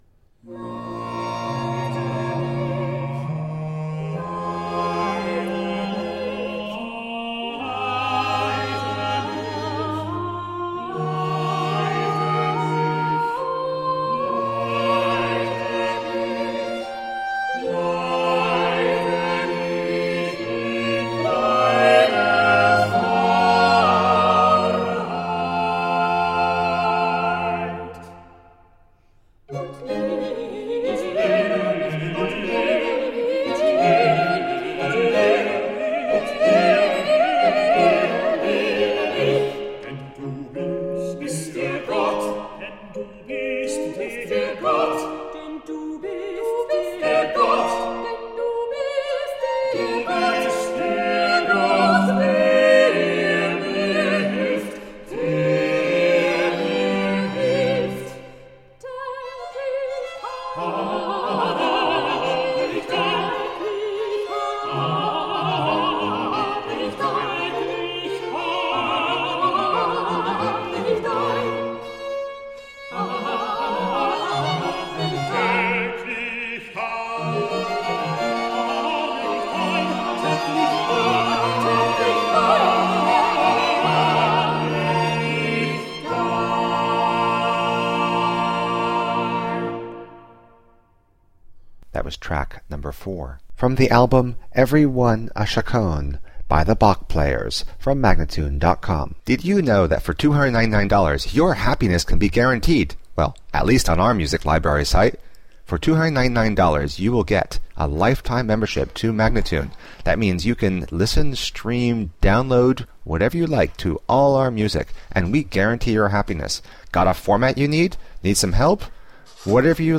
Baroque instrumental and vocal gems.